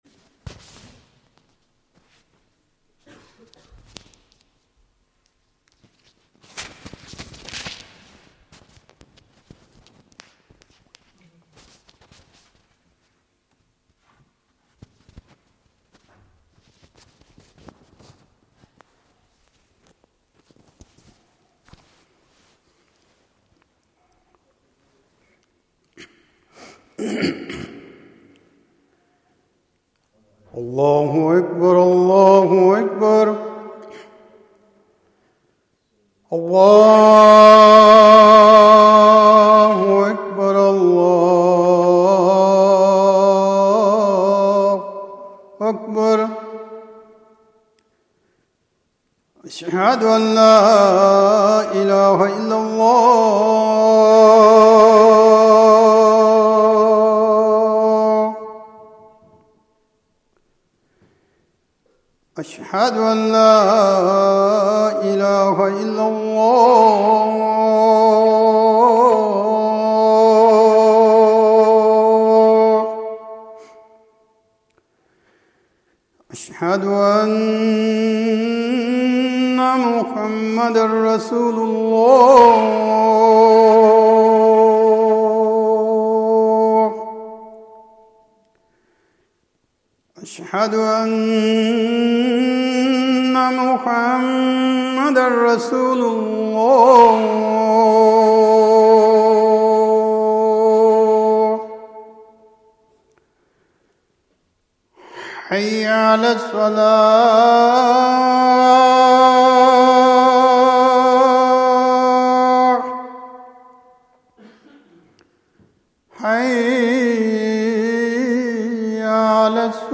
22nd Ramadan. Traweeh, Bayan, Nasheed.